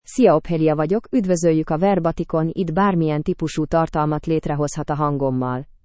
OpheliaFemale Hungarian AI voice
Ophelia is a female AI voice for Hungarian (Hungary).
Voice sample
Listen to Ophelia's female Hungarian voice.
Ophelia delivers clear pronunciation with authentic Hungary Hungarian intonation, making your content sound professionally produced.